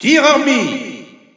Announcer pronouncing Mii Gunner's name with masculine pronouns in French.
Category:Mii Gunner (SSBU) Category:Announcer calls (SSBU) You cannot overwrite this file.
Mii_Gunner_M_French_Announcer_SSBU.wav